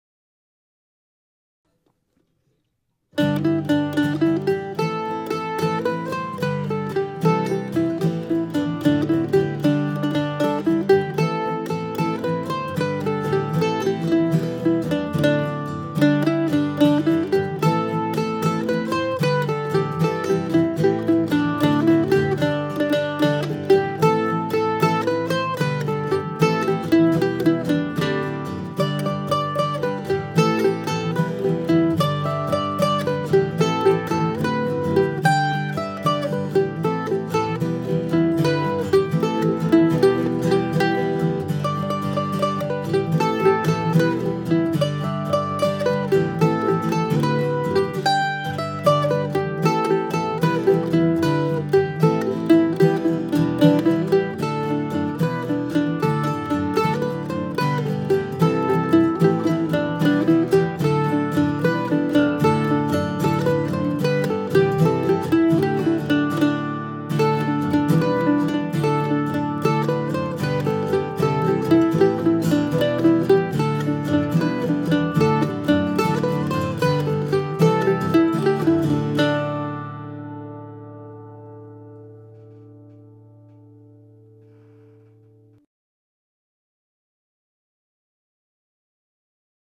THE BLARNEY PILGRIM | MANDOLIN